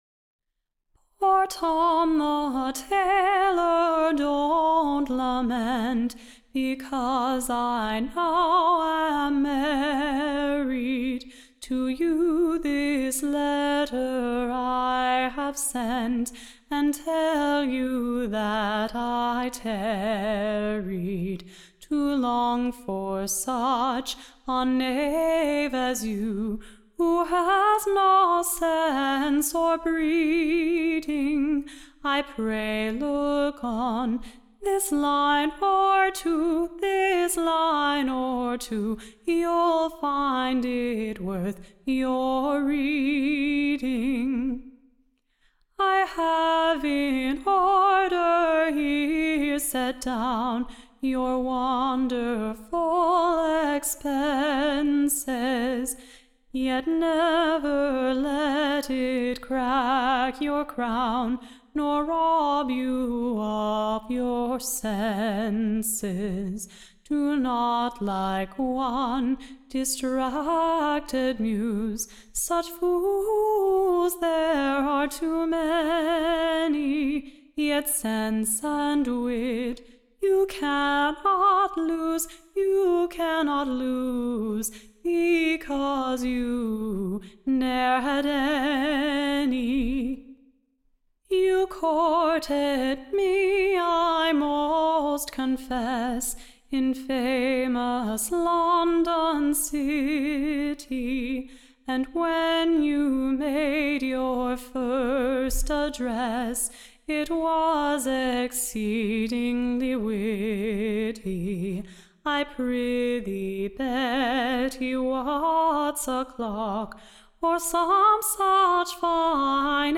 Recording Information Ballad Title Oxford-shire BETTY: / Containing her Joaking Letter to TOM the TAYLOR, / near Tower-Street; who she has fairly left in the Lurch, and married / with a Parson.